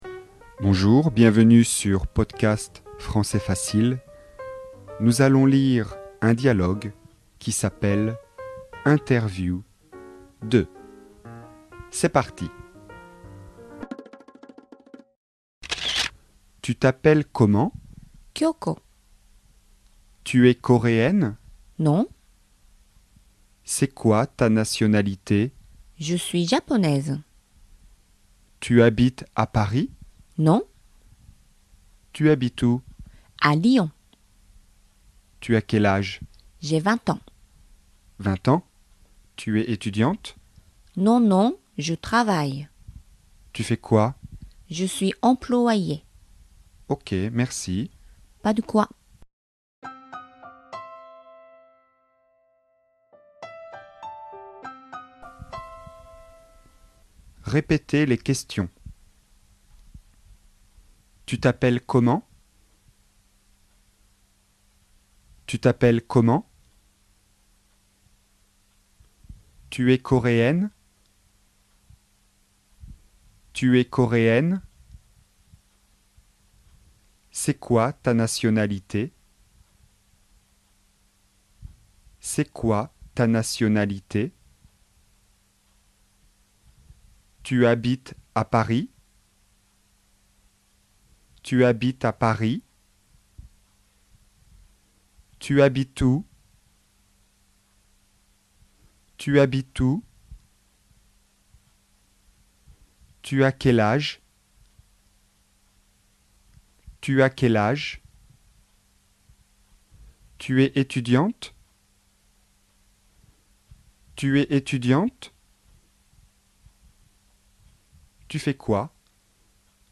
DIALOGUE :